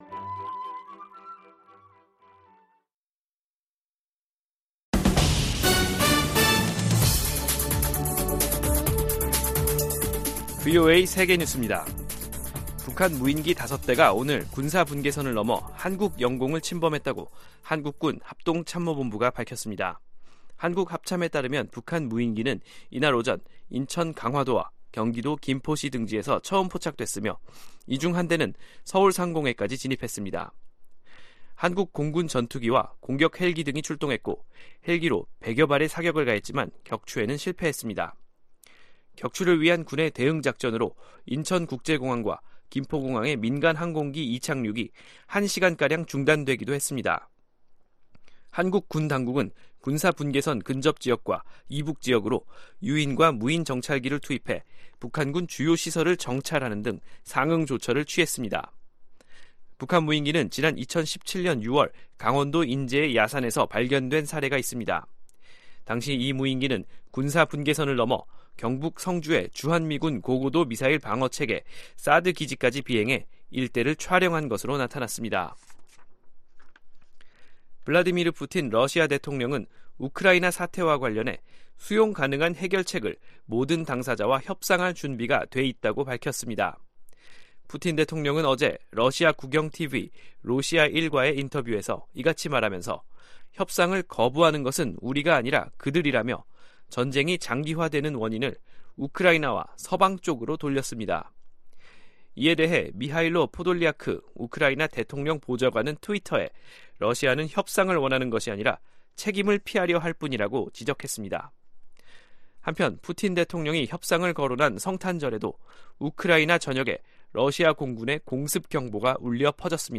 VOA 한국어 간판 뉴스 프로그램 '뉴스 투데이', 2022년 12월 26일 2부 방송입니다. 북한 무인기가 오늘 5년 만에 남측 영공을 침범해 한국군이 격추 등 대응작전을 벌였지만 격추에는 실패했습니다. 미국 국무부가 북한 정권의 단거리탄도미사일 발사를 규탄하면서 이번 발사가 유엔 안보리 결의에 위배된다고 지적했습니다.